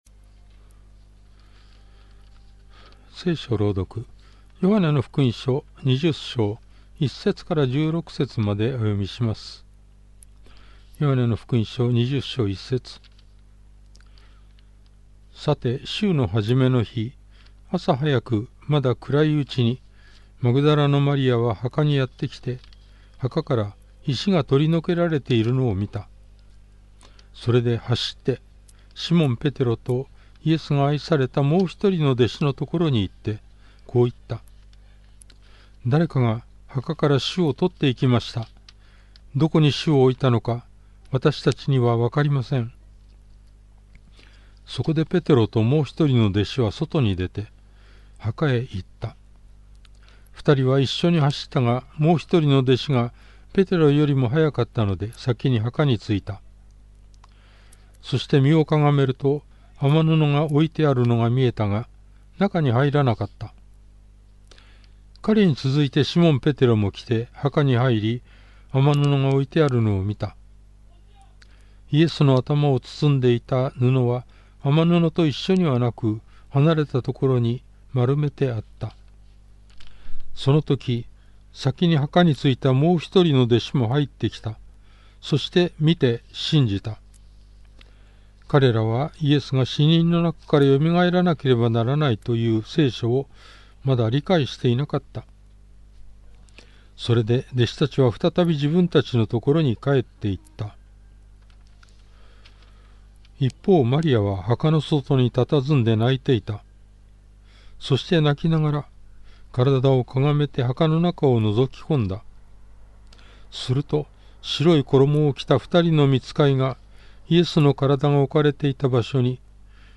BibleReading_J20.1-16.mp3